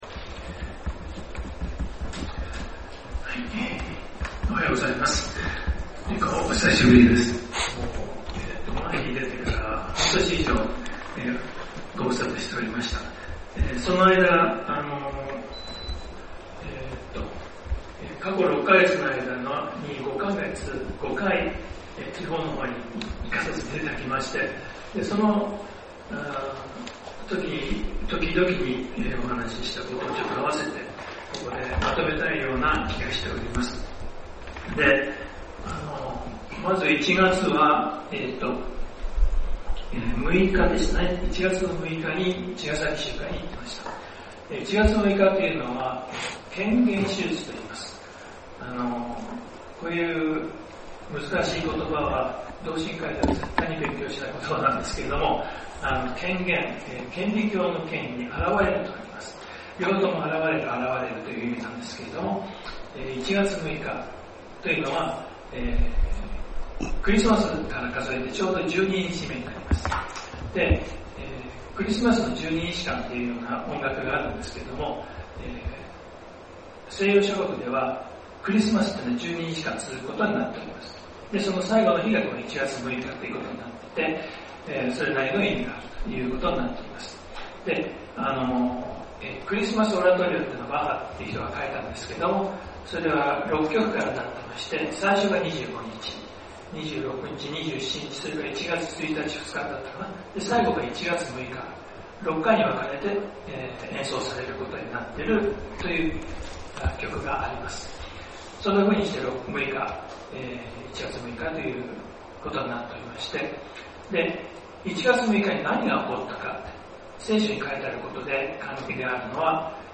先週，東京集会で行われた礼拝で録音された建徳です。